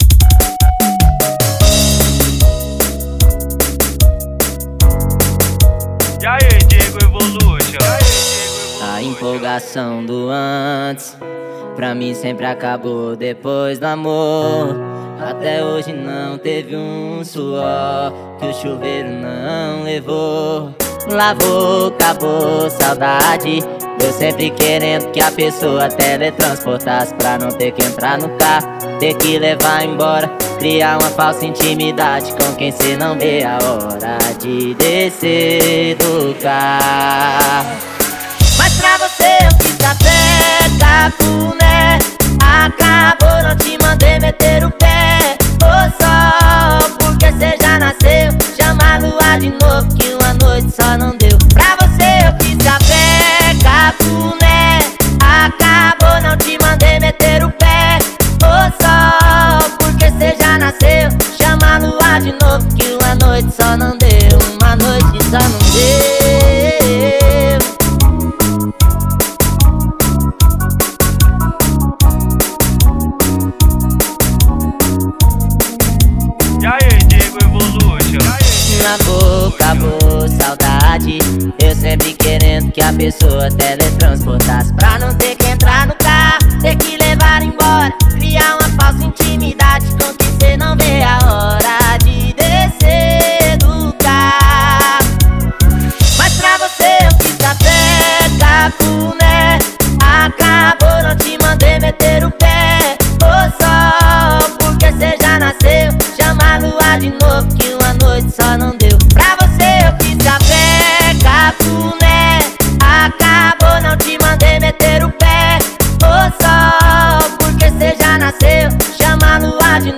OUÇA NO YOUTUBE Labels: Melody Facebook Twitter